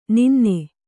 ♪ ninne